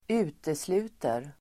Uttal: [²'u:teslu:ter]